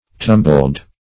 (t[u^]m"b'ld); p. pr. & vb. n. Tumbling (t[u^]m"bl[i^]ng).]